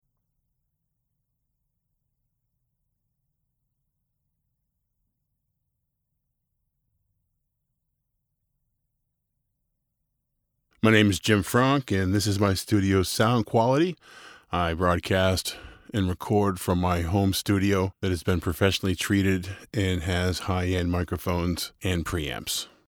Commercial
Male
American English
Assured, Authoritative, Character, Cheeky, Confident, Cool, Corporate, Deep, Engaging, Friendly, Gravitas, Natural, Reassuring, Sarcastic, Smooth, Streetwise, Wacky, Warm, Witty, Versatile
American English (native) Boston, New York, Southern & Texas accents
Microphone: Sennheiser 416, Neumann U87 and TLM 103